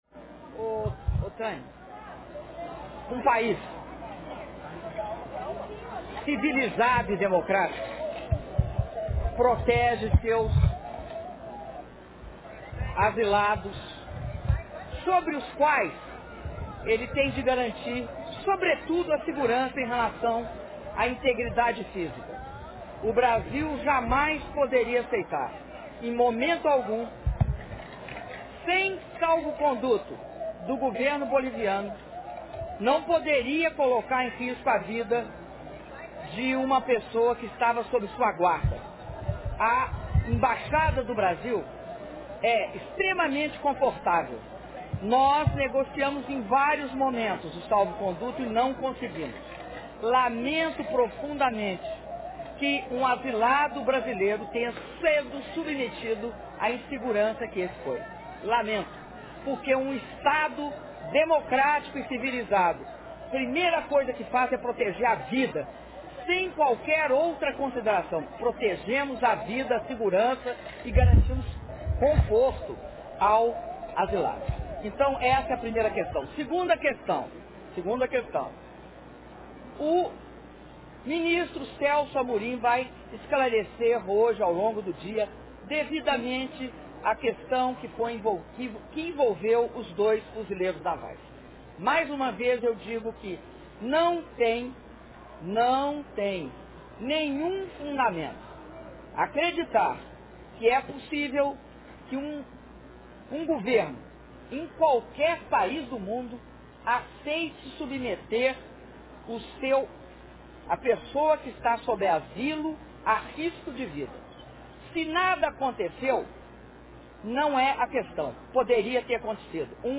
Entrevista coletiva concedida pela Presidenta da República, Dilma Rousseff, após a sessão solene do Congresso Nacional para entrega do Relatório Final da CPMI da Violência contra a Mulher